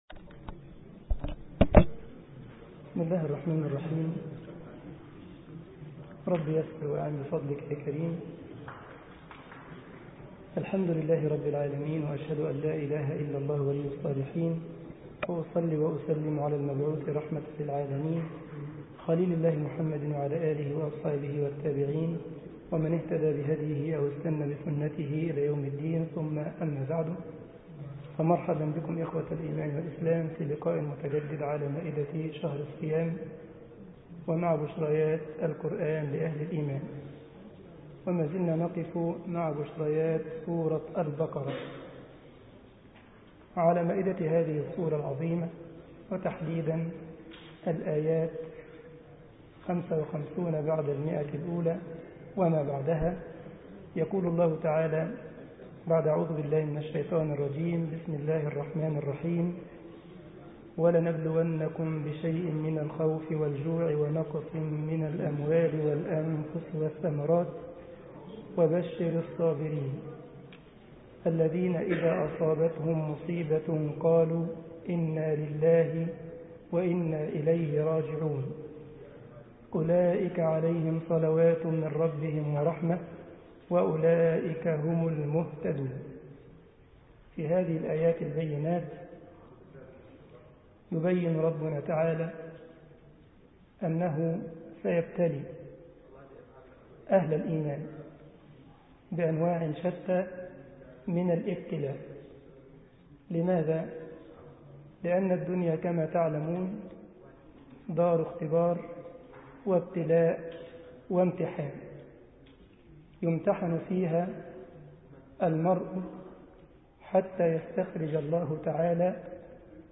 مسجد الجمعية الإسلامية بالسارلند ـ ألمانيا درس 16 رمضان 1433 هـ